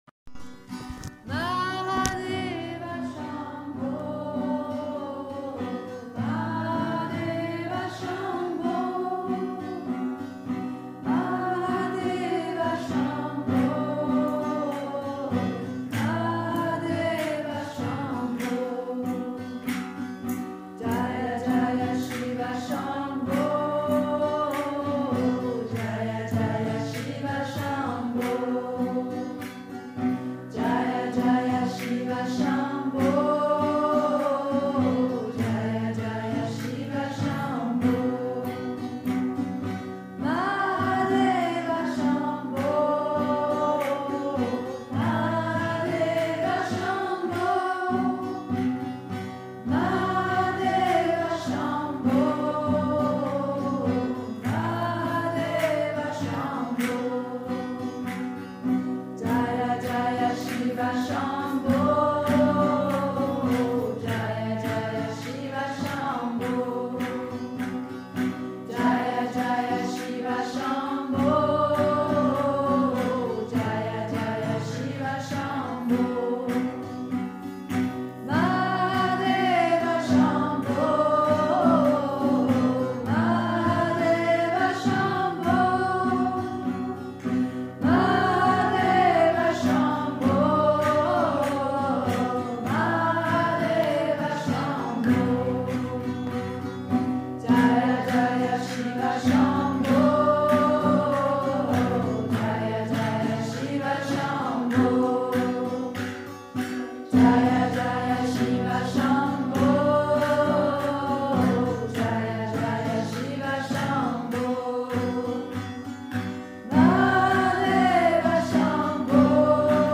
Les mantras et bhajans, chants sacrés indiens chantés lors d’une Matinée ou Soirée mantra sont une invitation vibrante à se tourner vers l’Essence de notre Être, à Ce qui est au delà des mots, Ce qui appelle au plus profond de nous.
Extrait d’une soirée Mantra
Chant à Shiva Shambo, demeure de la joie!